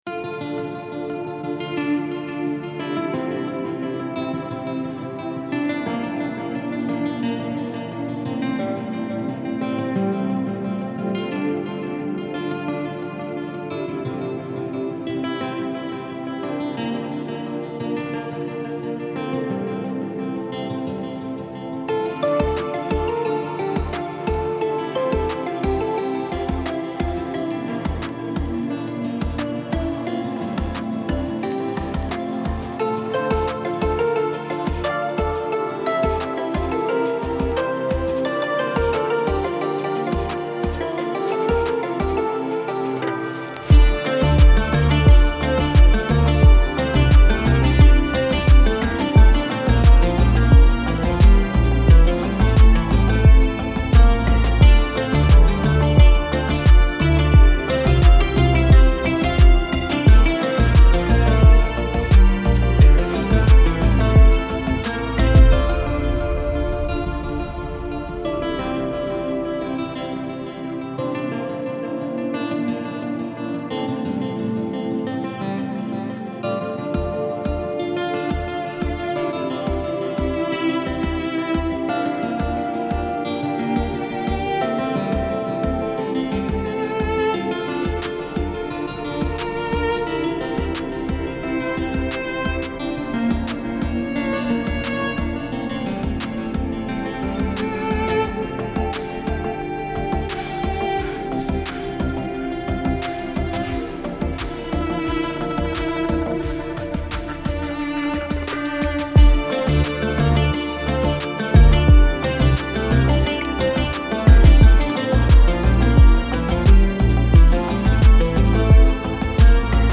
سبک دیپ هاوس , موسیقی بی کلام
موسیقی بی کلام ریتمیک آرام